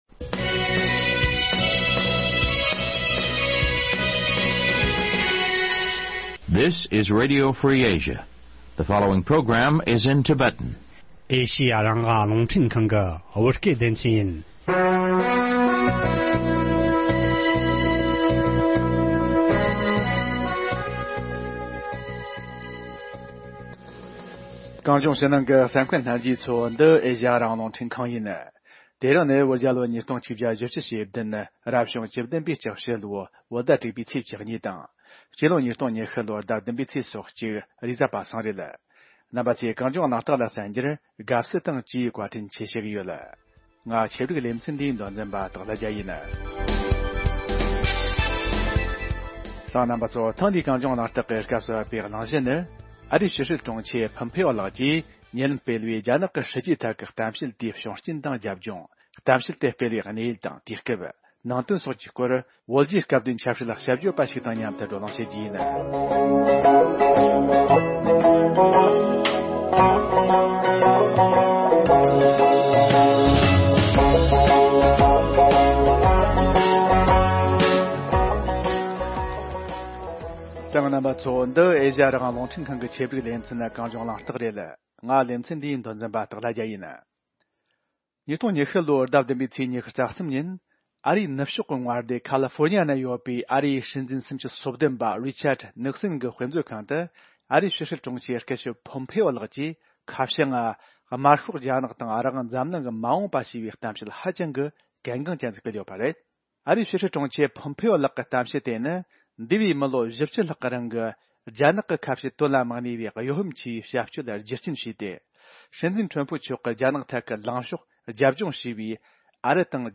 བགྲོ་གླེང་བྱས་པར་ཉན་རོགས་གནོངས།།